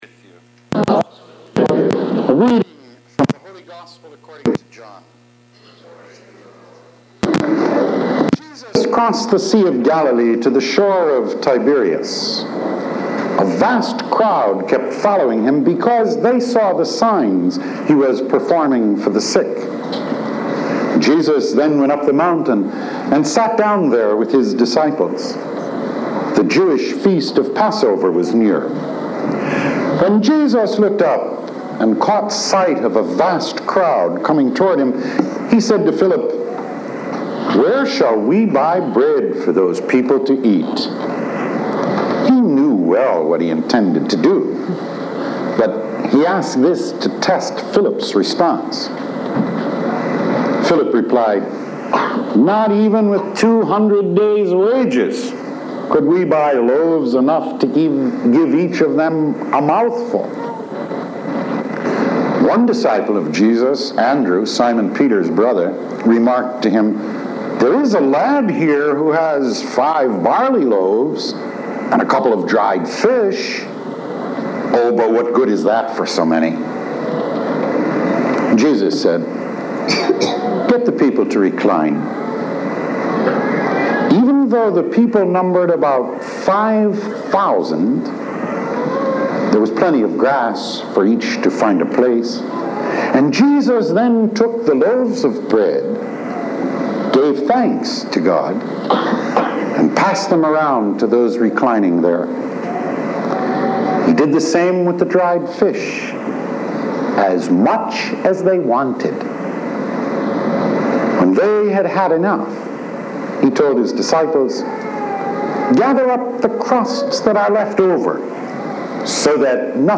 Sharing – Weekly Homilies
Originally delivered on June 30, 1991